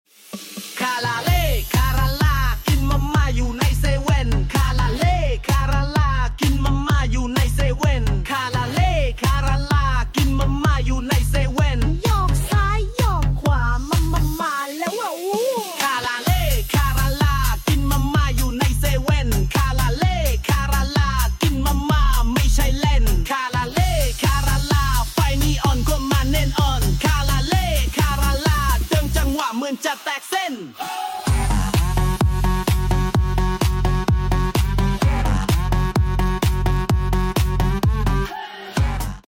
Fun Dance Song